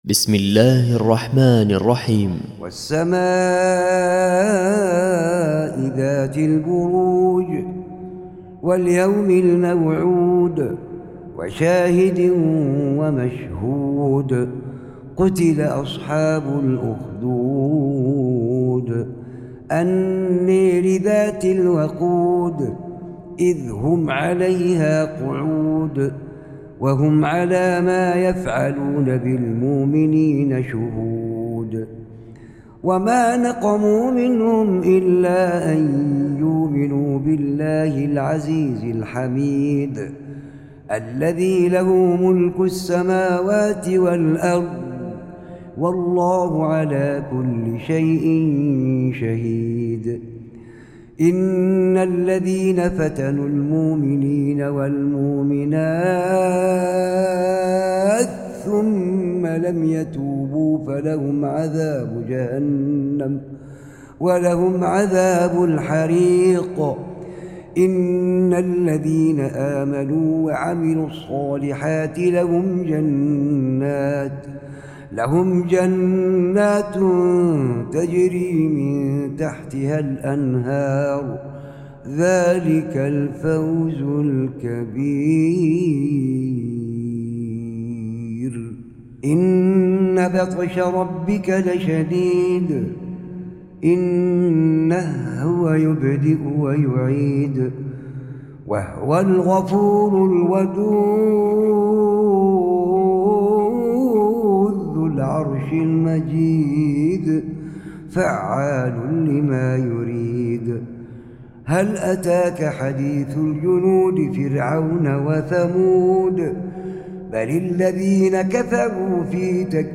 أتيتكم يا أحبتي بتلاوة طيبة ورائعة
من محراب مسجد علي بن جبر بمنطقة الحد
:: صلاة العشاء - سورة البروج ::